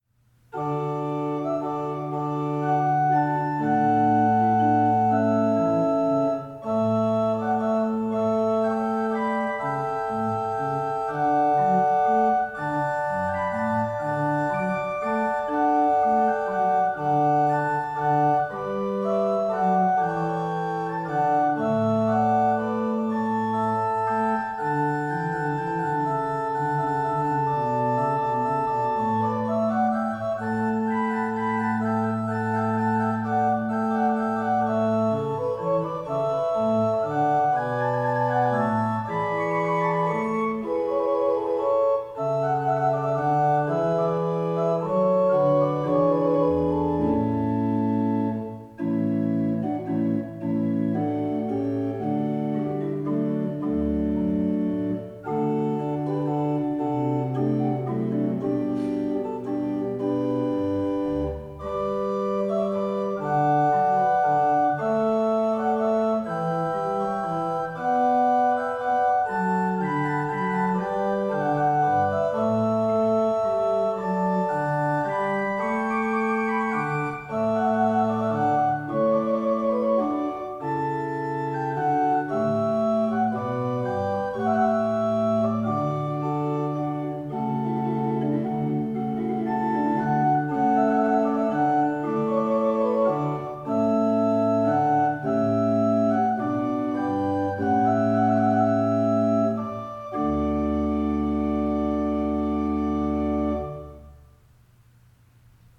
We’ve prepared this page to help you choose organ music for your wedding ceremony.
D. Soft and Lovely